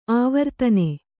♪ āvartane